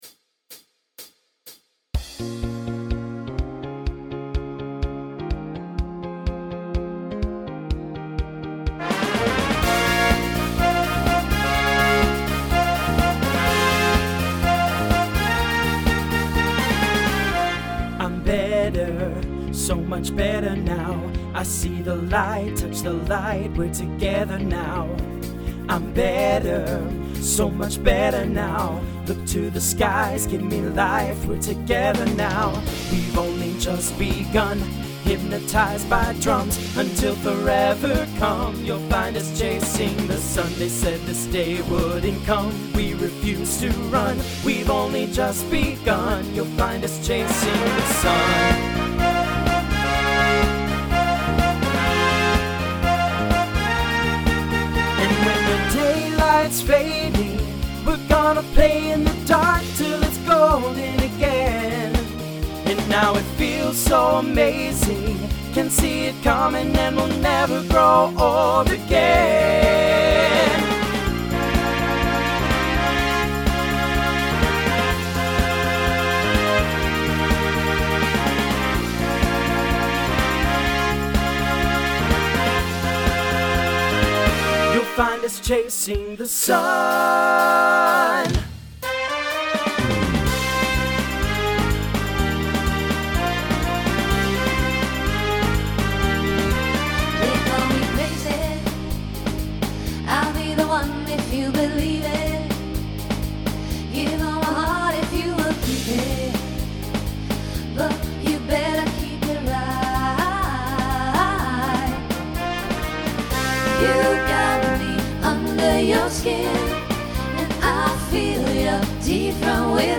TTB/SSA
Instrumental combo
Pop/Dance , Rock